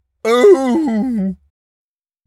seal_walrus_death_04.wav